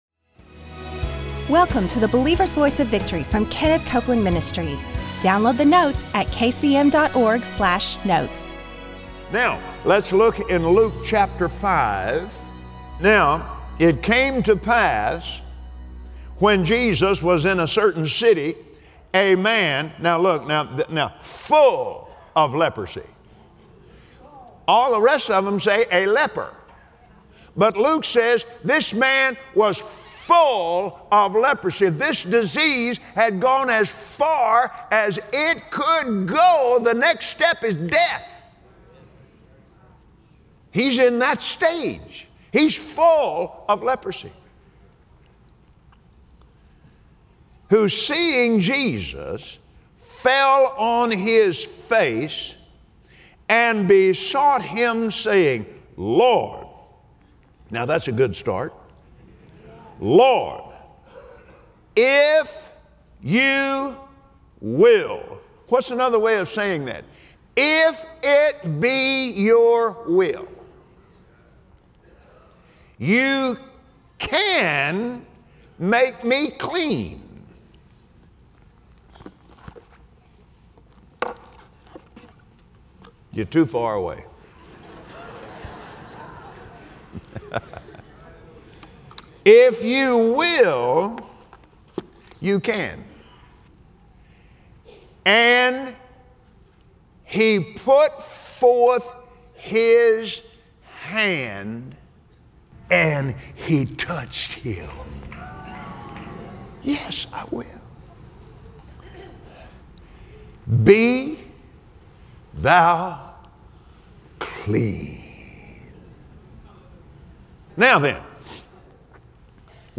You are supposed live well. Build your faith to receive from God and start living in His strength and peace, as Kenneth Copeland shares about the healing power of God on today’s Believer’s Voice of Victory.